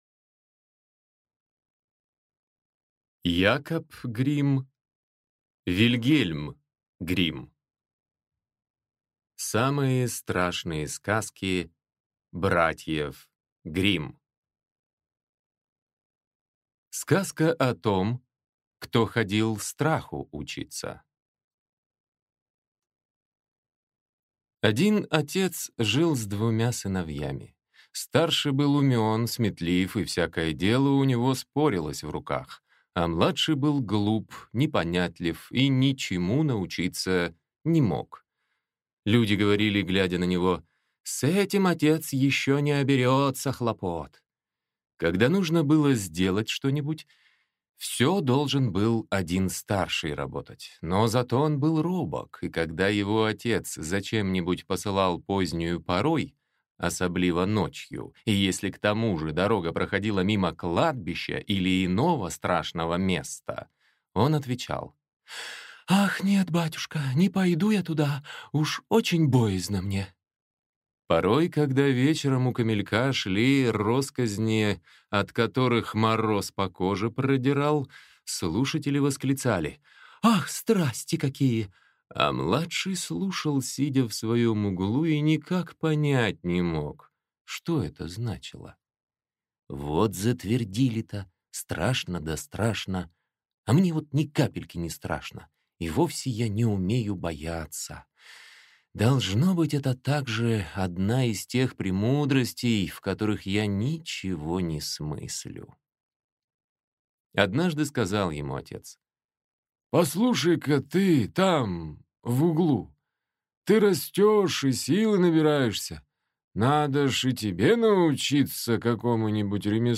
Аудиокнига Самые страшные сказки Братьев Гримм | Библиотека аудиокниг